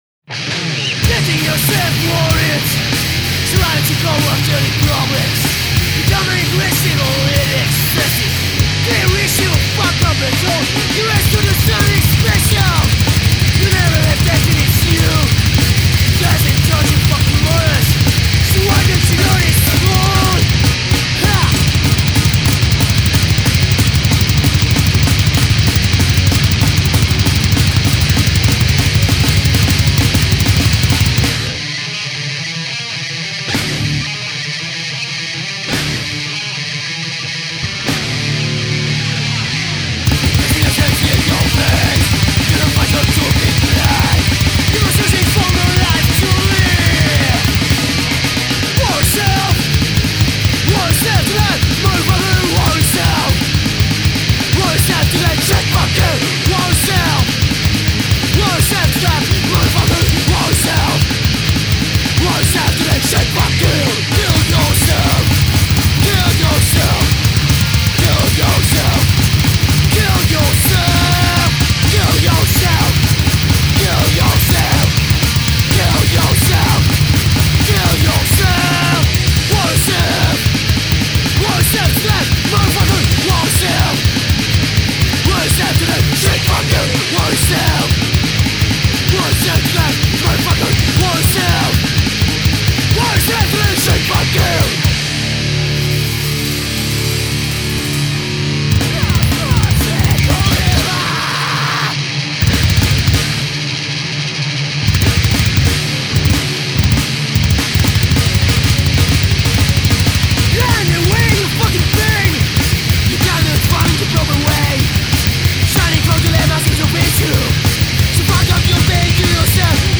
Metal!